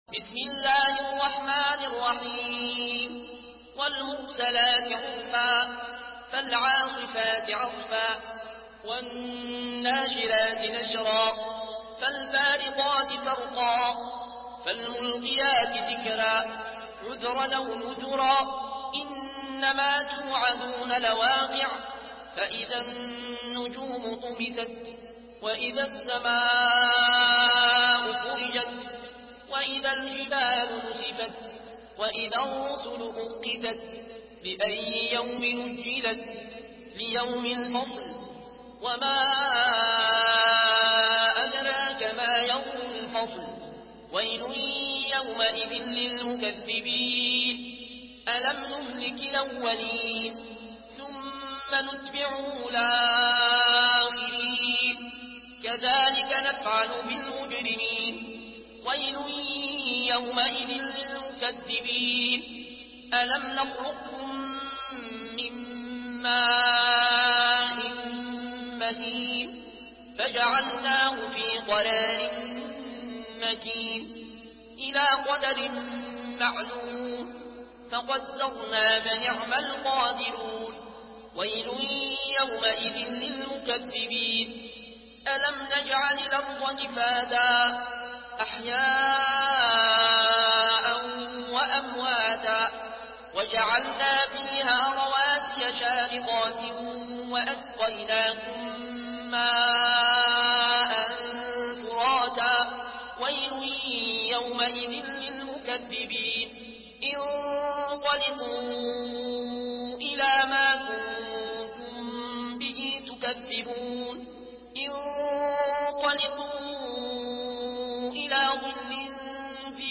Murattal Warsh An Nafi From Al-Azraq way